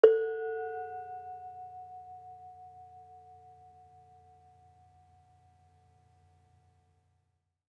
Bonang-A3-f.wav